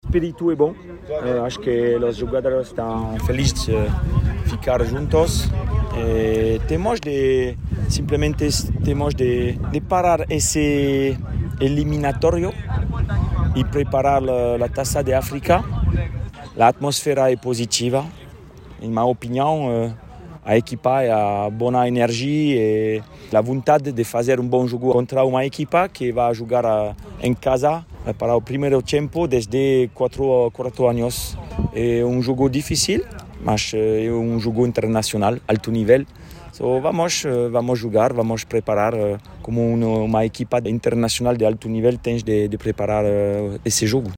Declarações de Patrice Beuamelle, técnico dos Palancas, que hoje defronta o Eswatini.